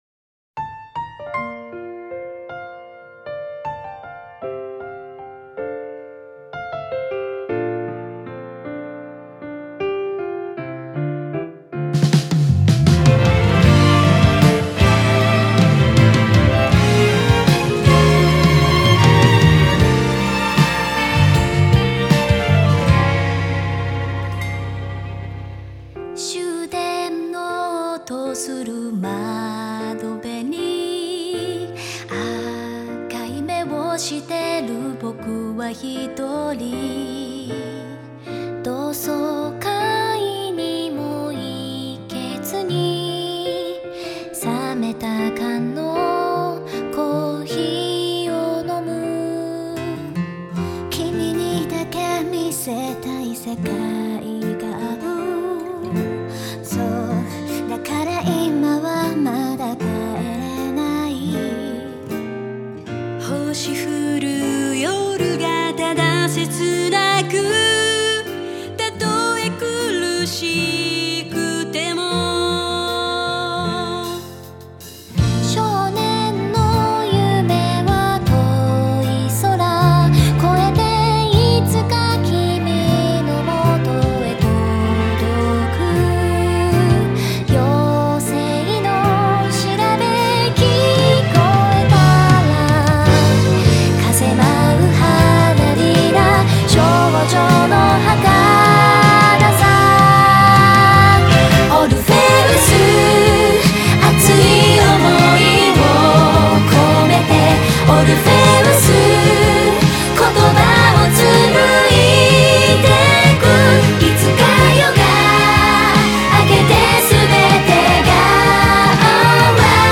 음악 공간/J-POP